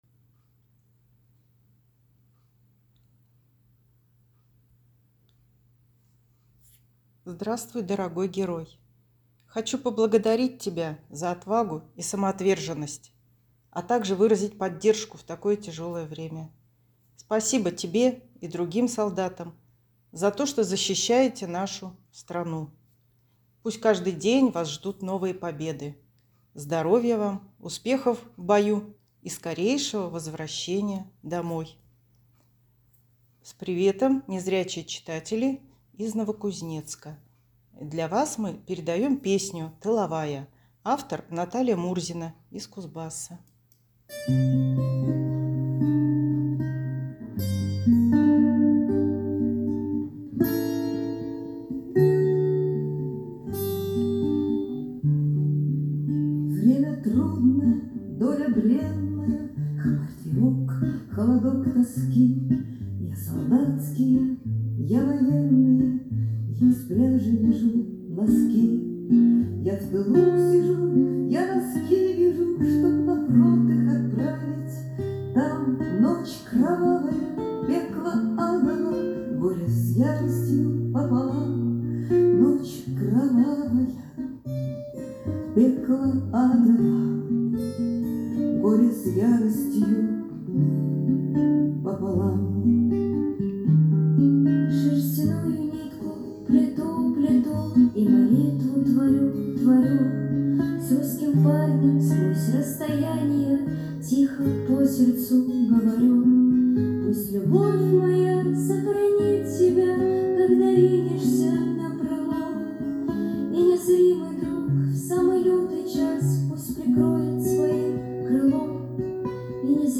Незрячие и слабовидящие читатели пишут «звуковые» письма в поддержку героев СВО.